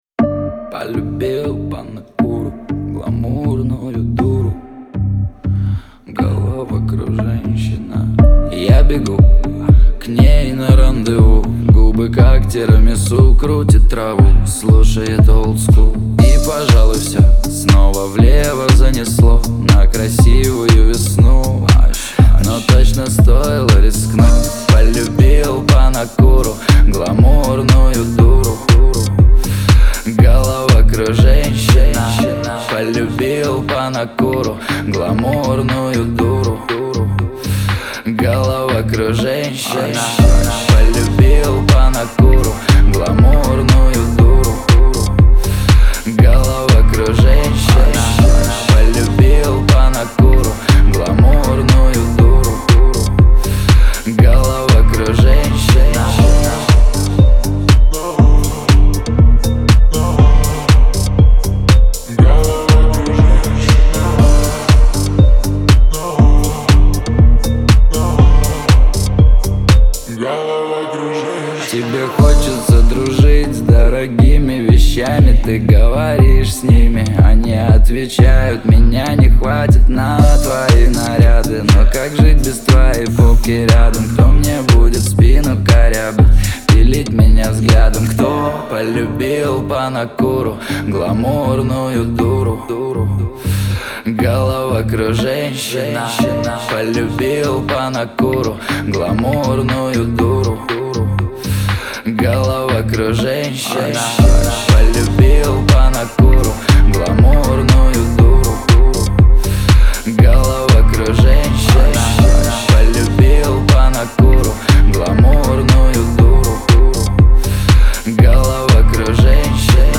это зажигательная песня в жанре хип-хоп